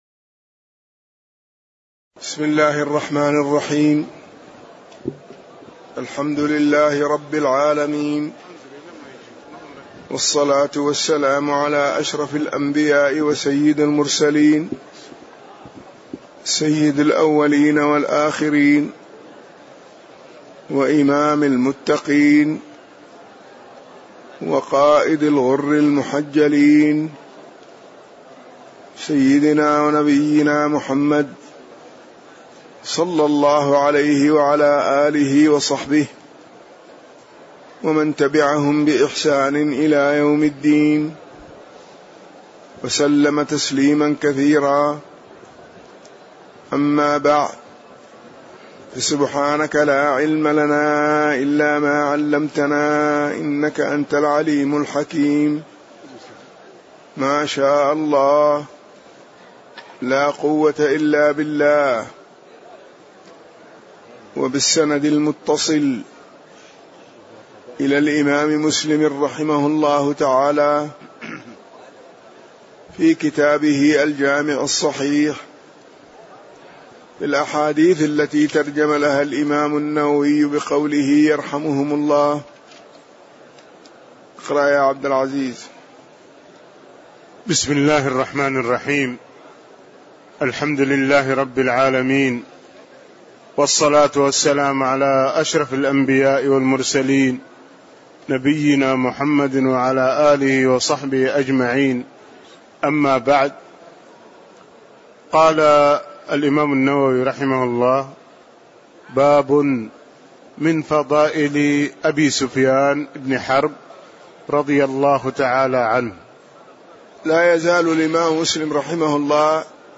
تاريخ النشر ٥ ذو القعدة ١٤٣٧ هـ المكان: المسجد النبوي الشيخ